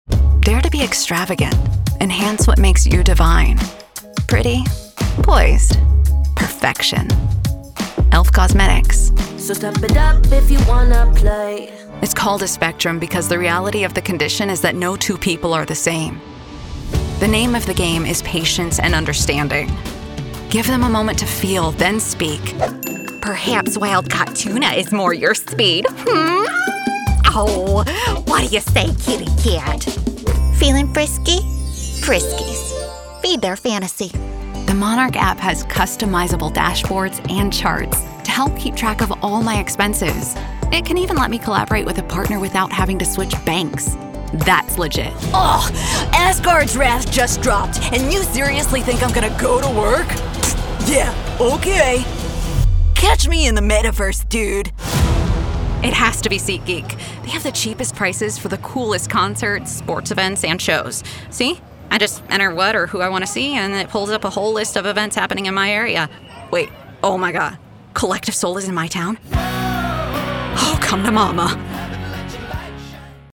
Warm, emotional, personal
Commercial